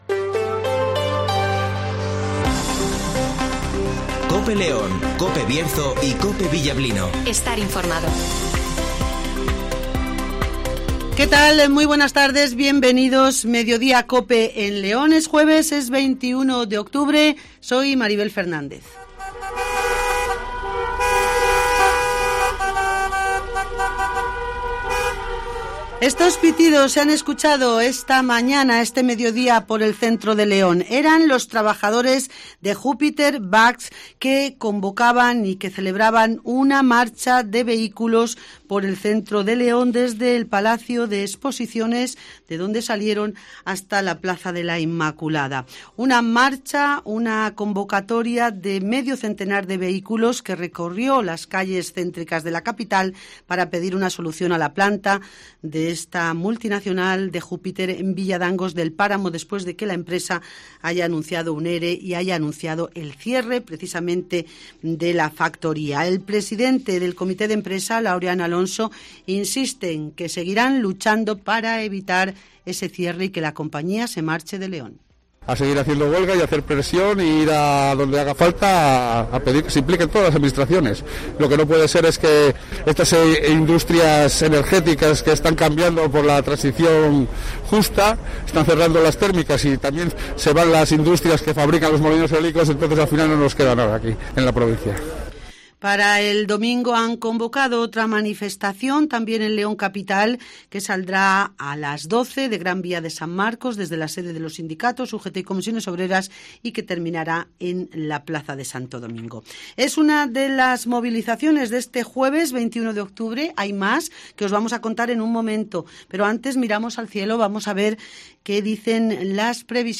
Pitidos manifestación trabajadores de la empresa " Jupiter Bach "